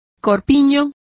Complete with pronunciation of the translation of bodices.